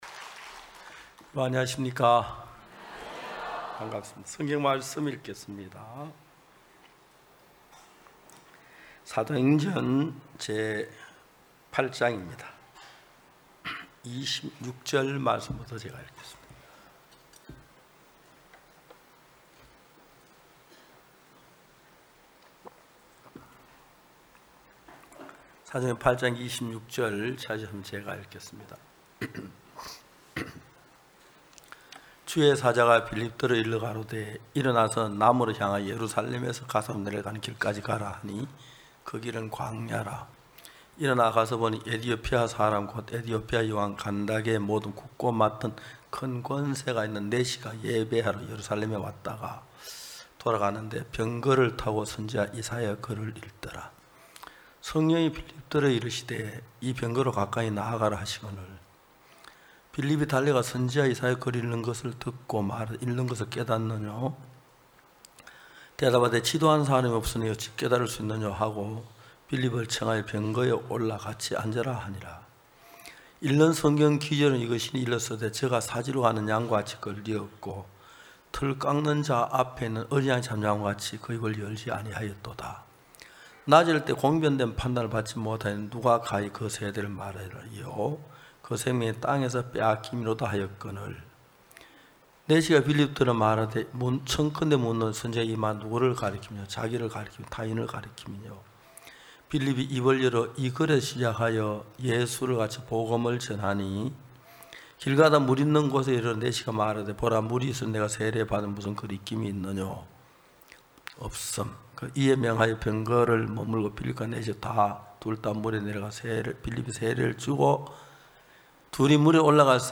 GoodNewsTV Program 2024 후반기 서울 성경세미나 #2 지도하는 사람이 없으니 어찌 깨달을 수 있느뇨
성경세미나 설교를 굿뉴스티비를 통해 보실 수 있습니다.